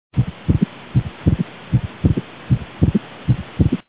Südametoonide helisalvestused (H.)